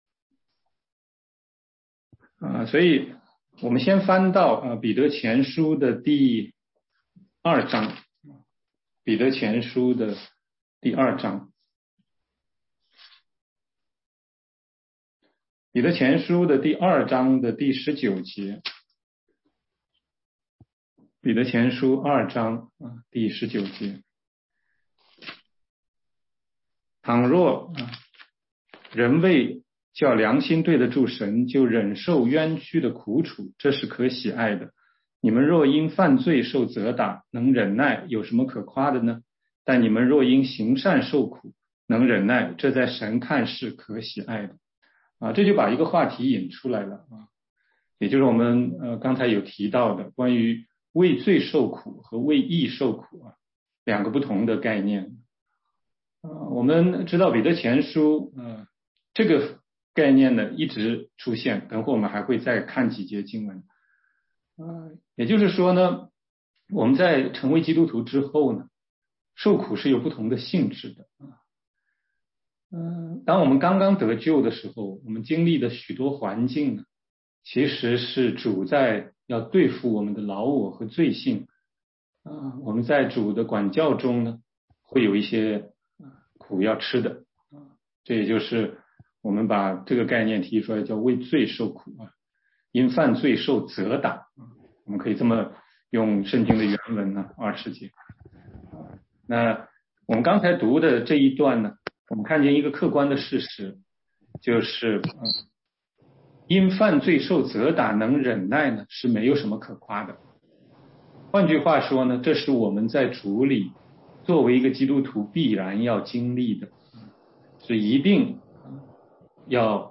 16街讲道录音 - 基督徒成圣的第一个阶段：与罪断绝（全中文）